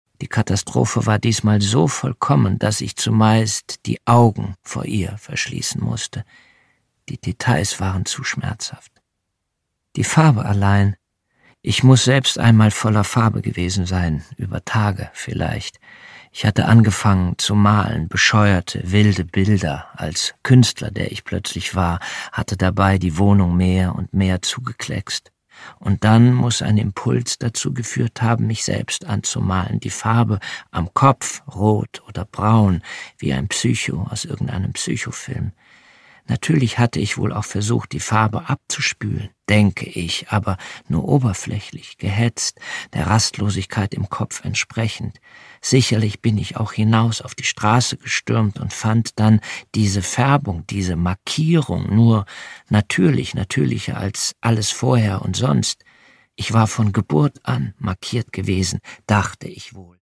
Produkttyp: Hörbuch-Download
Gelesen von: Jens Harzer